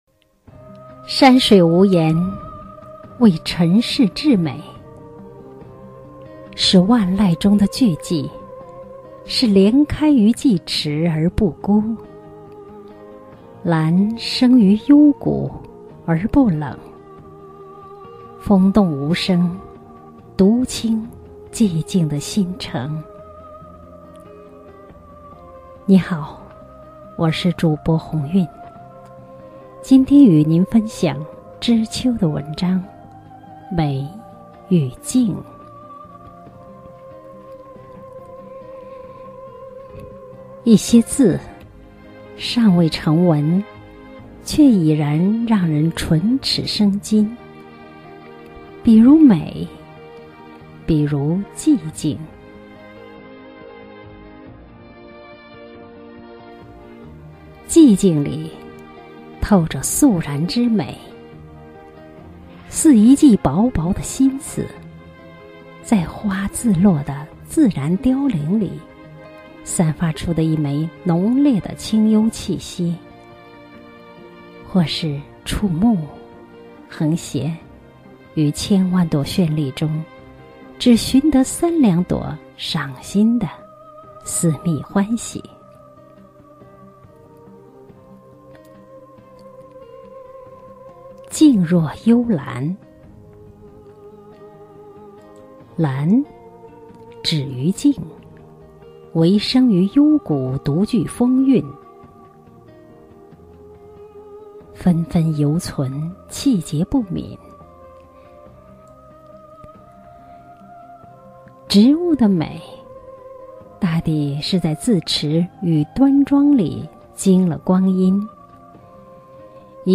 朗诵｜美与寂静-文学书画-智慧登封论坛网 -